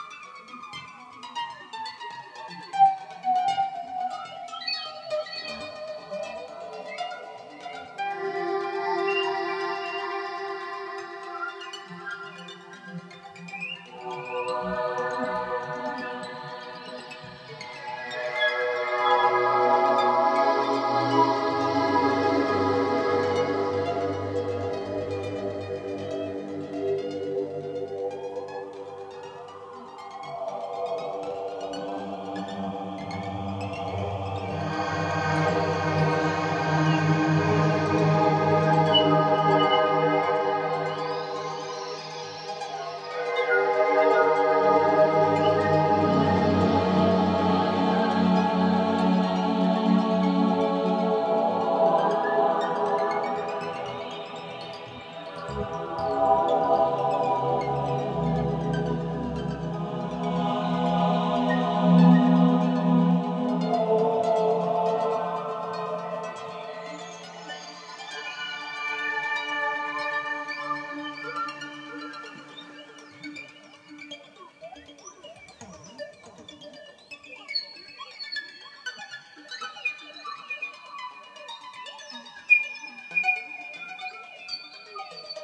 Home synth.